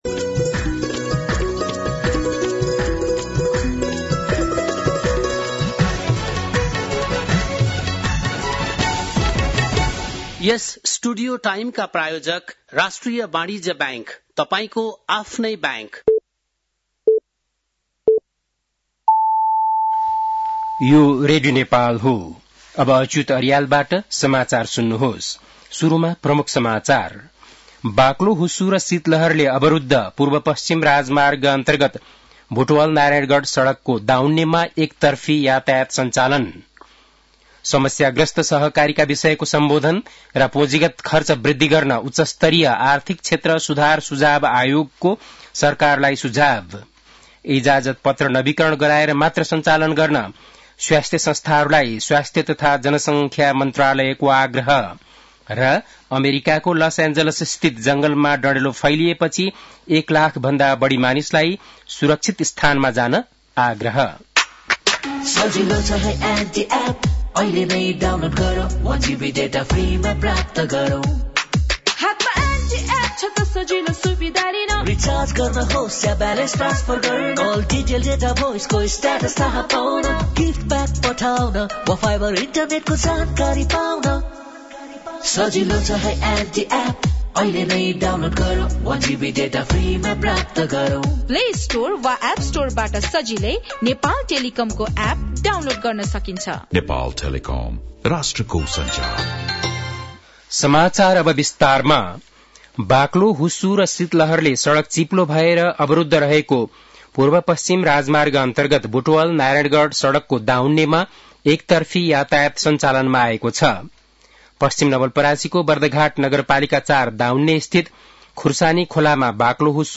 बेलुकी ७ बजेको नेपाली समाचार : २५ पुष , २०८१
7-PM-Nepali-News-9-24.mp3